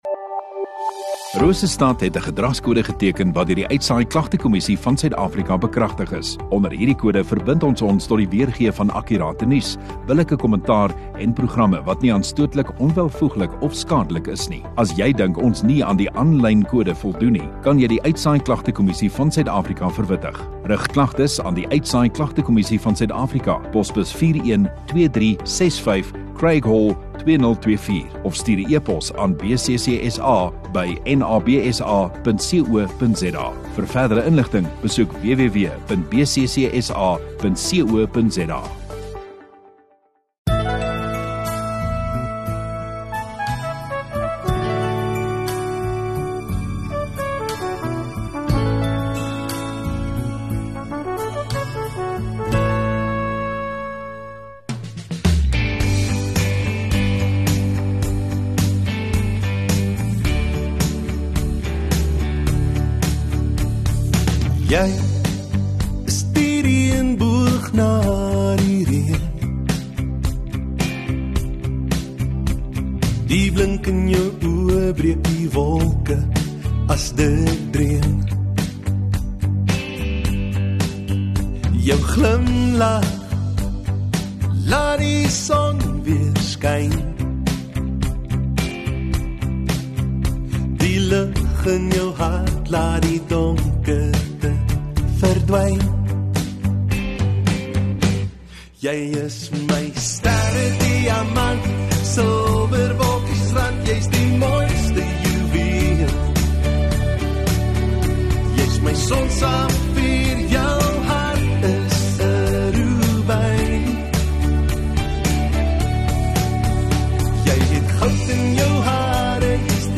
4 Jul Vrydag Oggenddiens